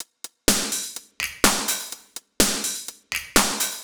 Index of /musicradar/retro-house-samples/Drum Loops
Beat 08 No Kick (125BPM).wav